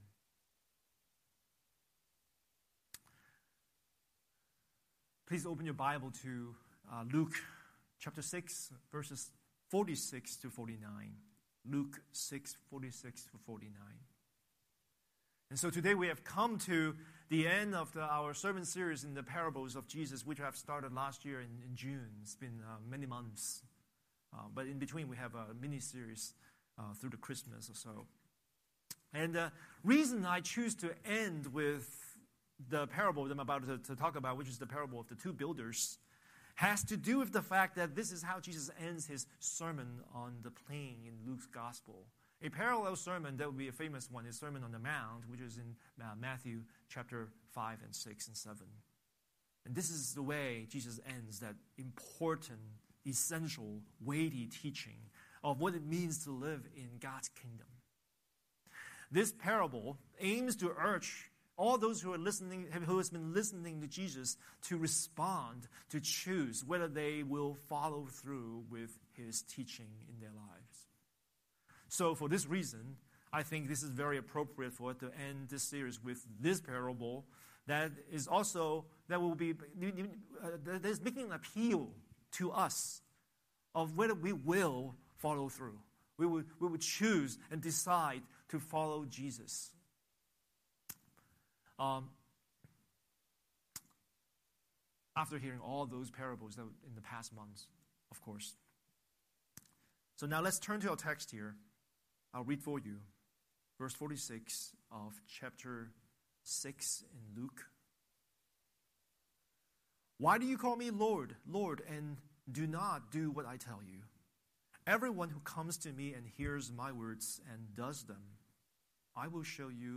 Scripture: Luke 6:46–49 Series: Sunday Sermon